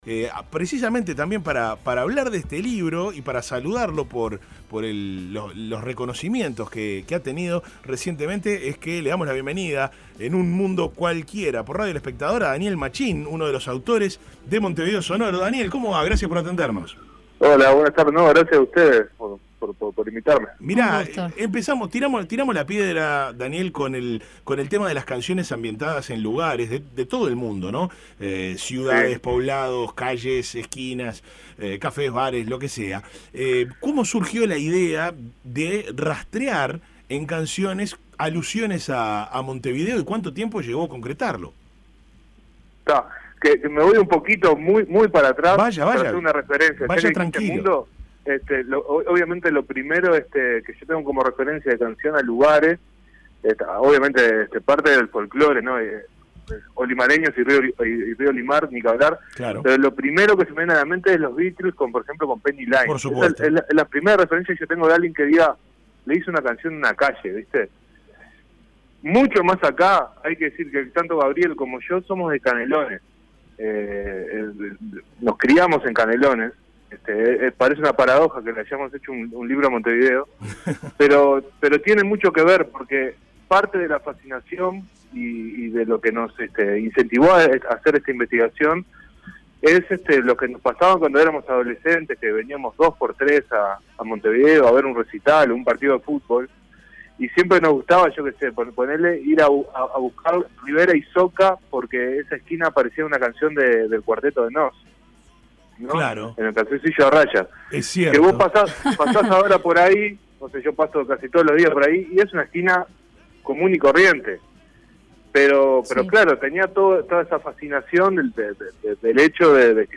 conversó con Un Mundo Cualquiera sobre esta publicación que retrata la identidad barrial, ayuda a imaginar momentos y épocas históricas.